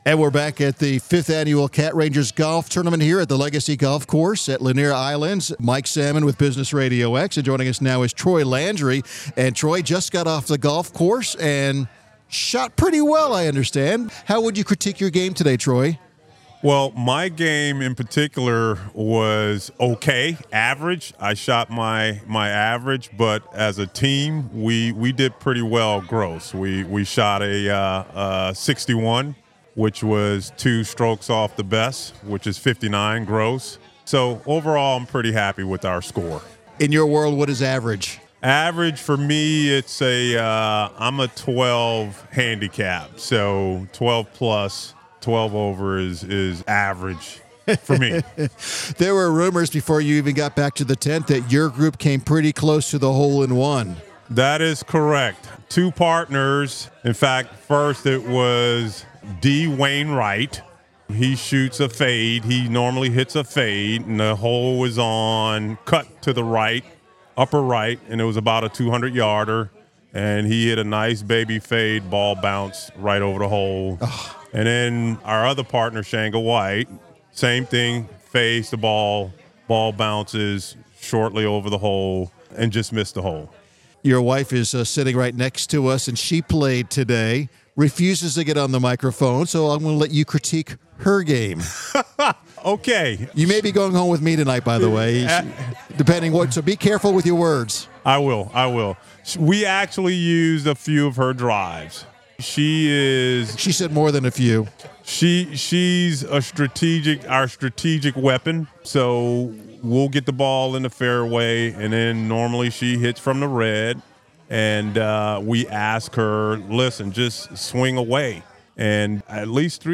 Gwinnett Business RadioX was on site interviewing golfers, sponsors, and others enjoying the event to raise money for the non-profit cat rescue organization.
Golfer